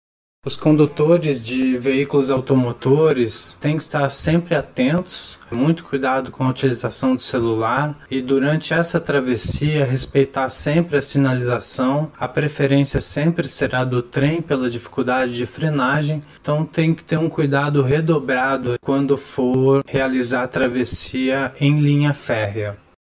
O que diz o especialista em Trânsito
Diante dessa norma, o especialista em Trânsito também falou dos cuidados que motoristas, motociclistas e ciclistas devem ter.